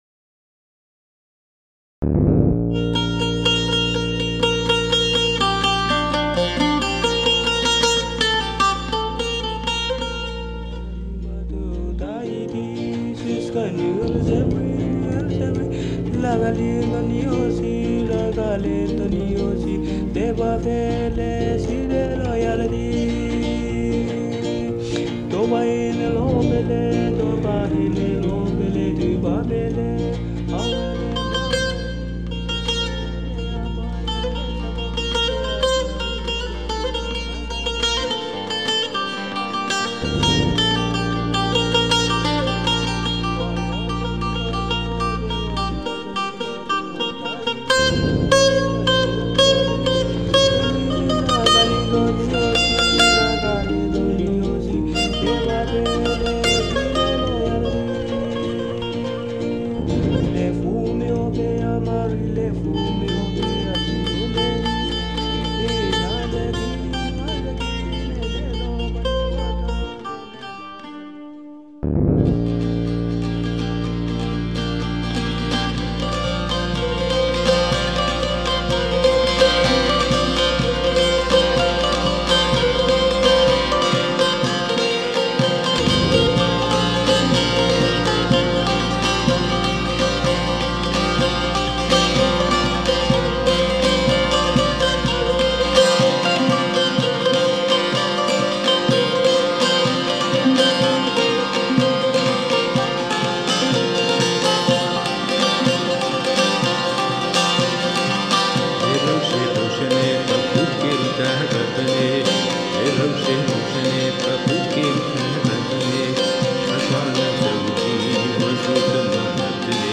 For this recording, I played the oldest instrument among Yazidi people called Tembûr. It is a collection of wood, strings, and animal veins alongside human ability to bring sound to it.